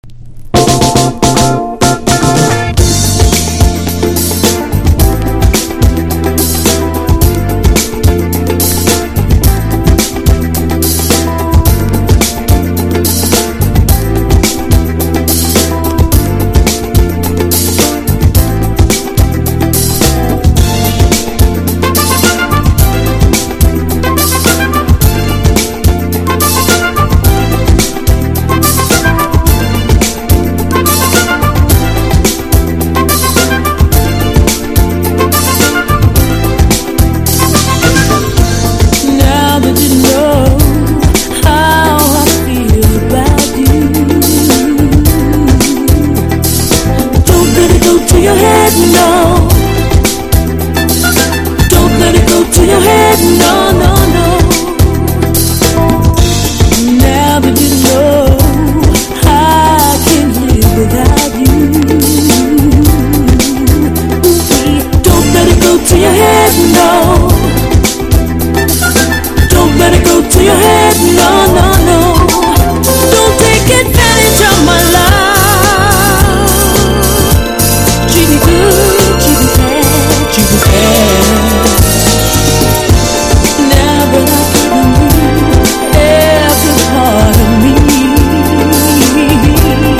ACID JAZZ